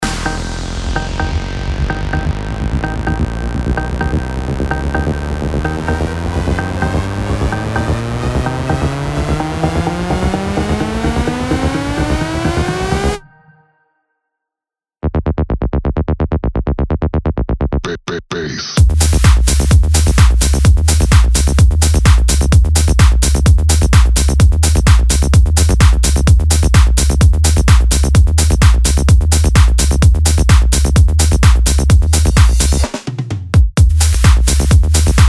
DJ and producer of tech house & house music
His style is unique and electrifying.